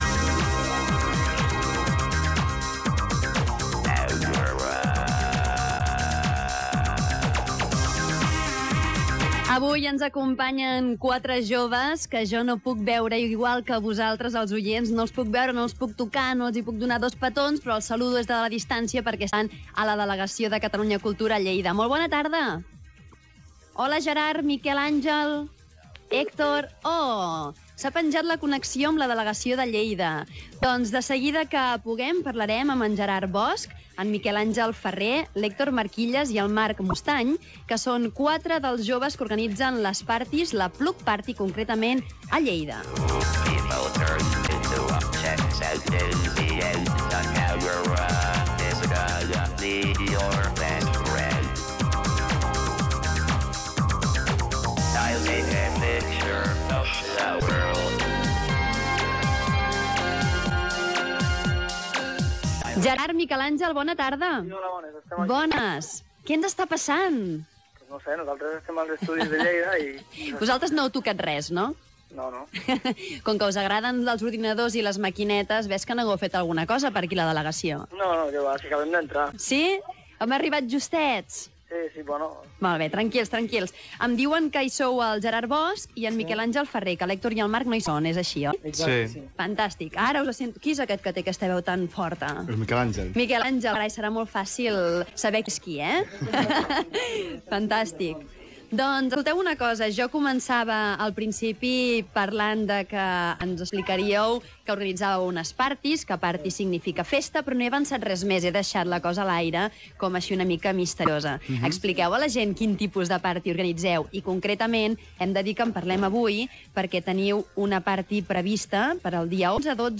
Interview about Plug Party v3 listened at Catalunya Radio.(in Catalan)
Plug Party v3 - Entrevista Catalunya Cultura.mp3